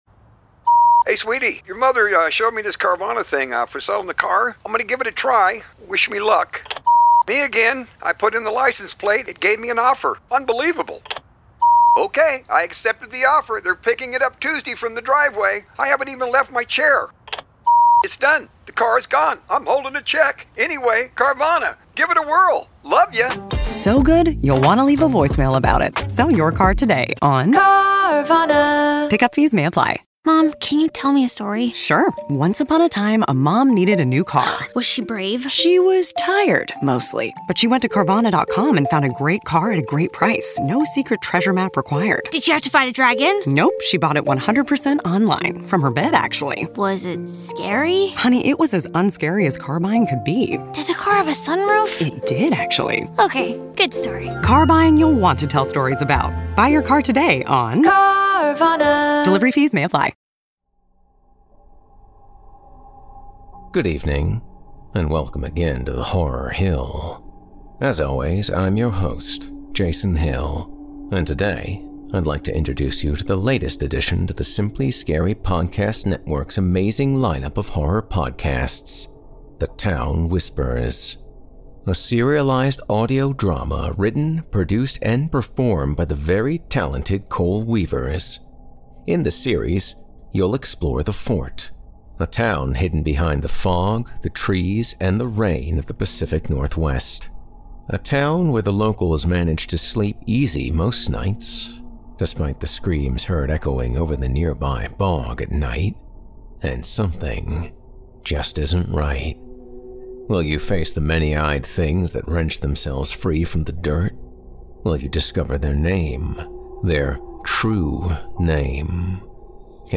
The Town Whispers is a horror audio drama that exists at the crossroads between Eldritch terrors, and folk horrors.